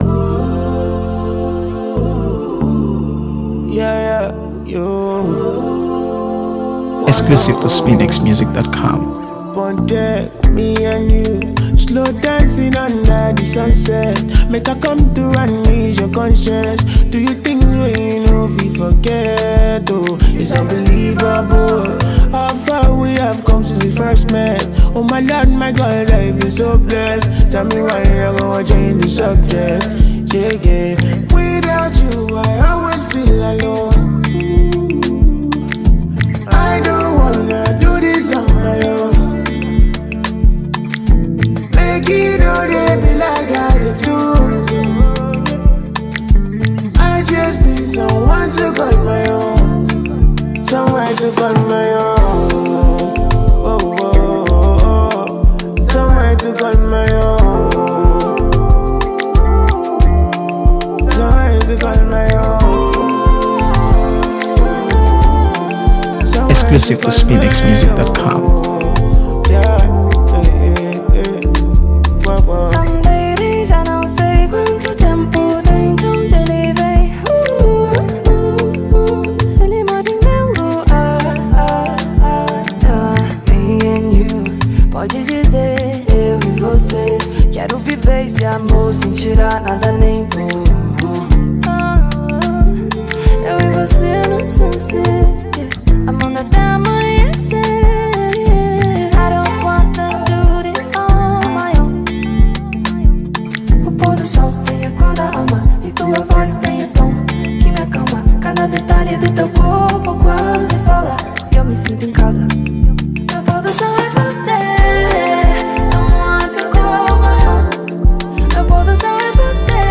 AfroBeats | AfroBeats songs
and it stands out not just for its lush production
creating a track that’s equal parts smooth and memorable.